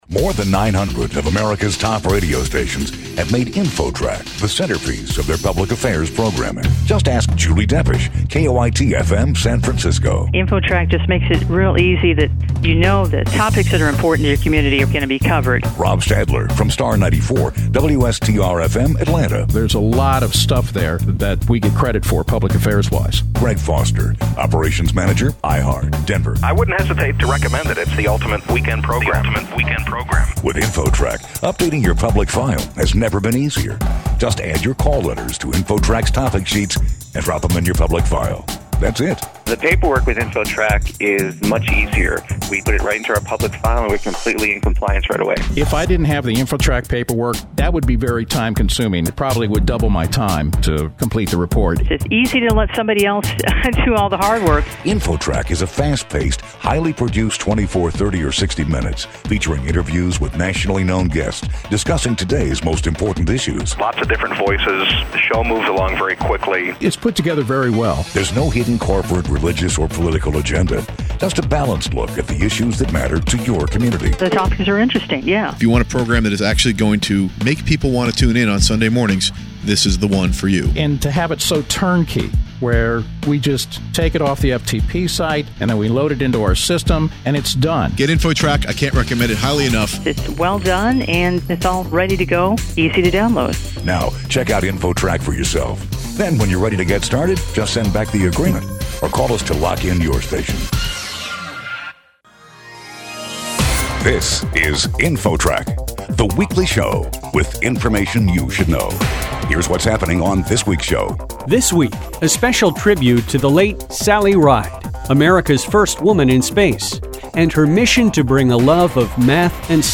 INFOTRAK is an award-winning, highly produced public affairs show, available in either a one hour or 30 min. version.
This weekly nationally syndicated Public Affairs radio show features interviews with experts and authorities, discussing topics of interest to most Americans. Fast paced and lively, INFOTRAK strives to present impartial, balanced coverage of every story.
The show announcer is the legendary Charlie Van Dyke.